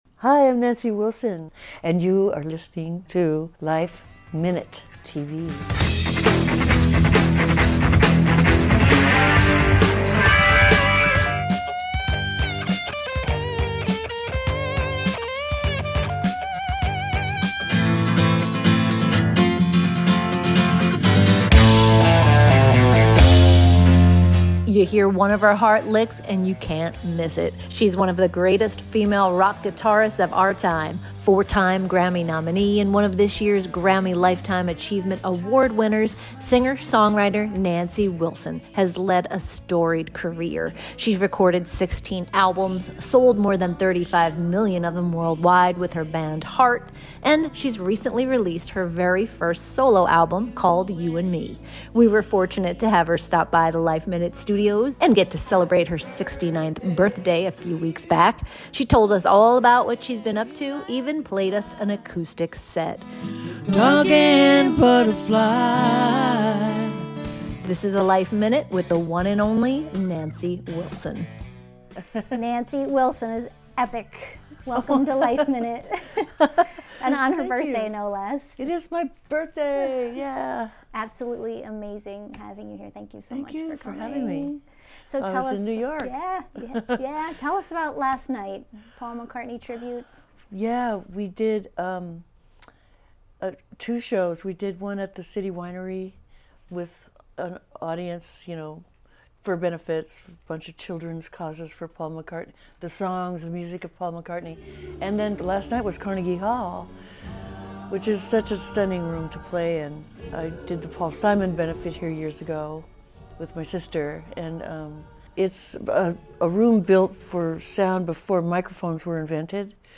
We were fortunate to have her stop by the LifeMinute studios and hear all about it, get to celebrate her birthday, play us an acoustic set and tell us everything else she's been up to; including touring with her band, a brand new management company; called Roadcase, and hopes of a possible Heart reunion.